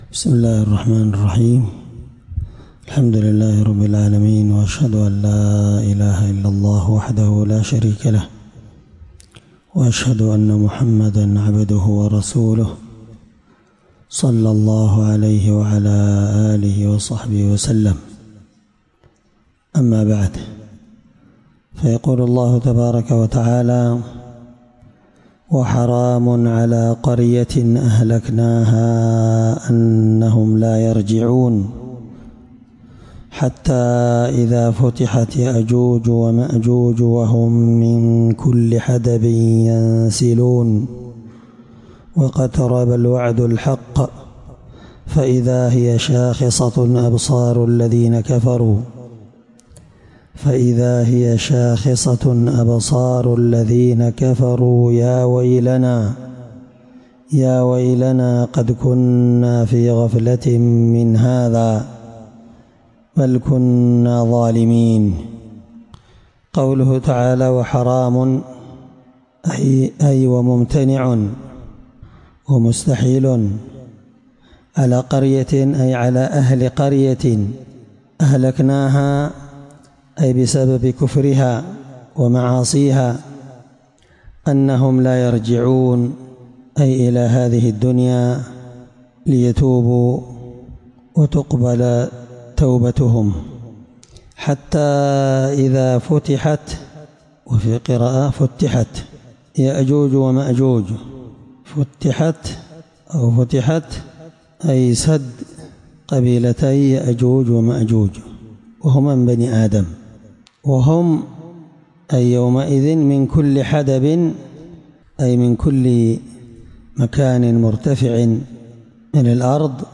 مع قراءة لتفسير السعدي